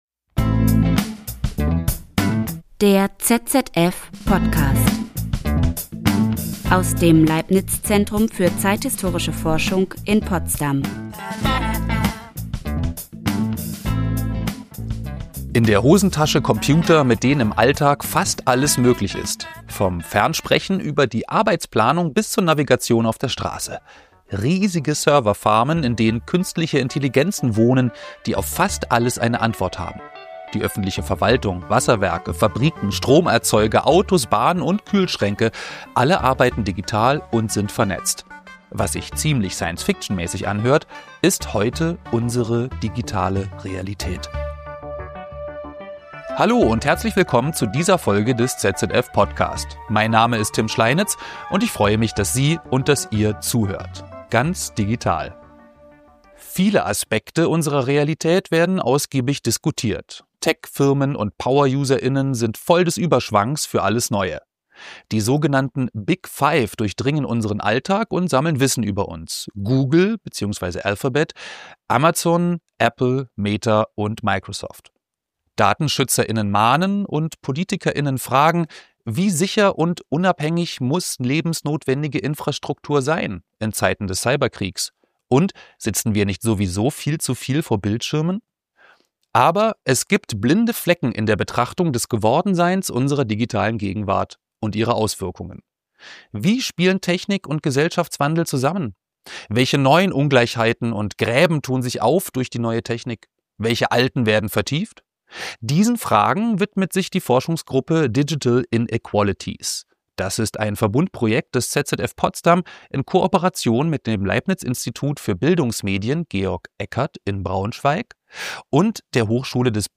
spricht mit den Geschichtswissenschaftler:innen